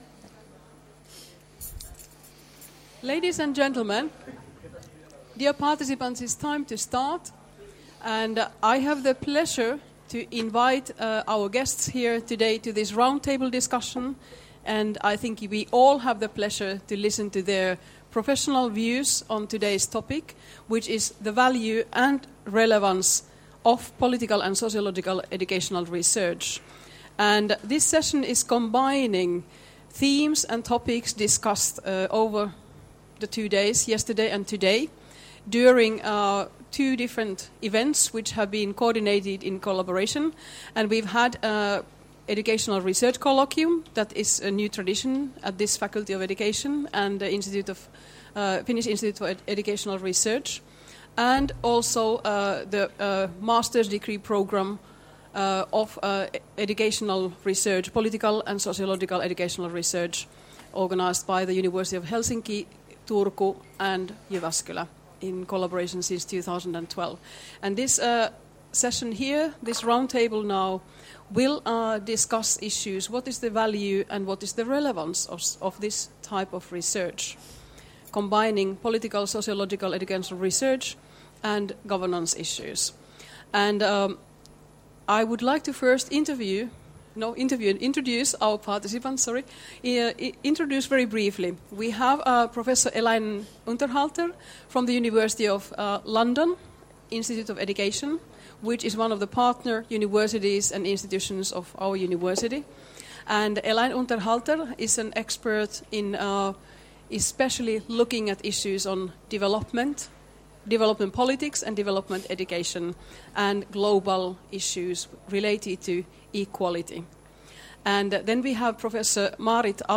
Roundtable Discussion: The Value and Relevance of Political and Sociological Educational Research 14.4.2016